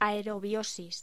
Locución: Aerobiosis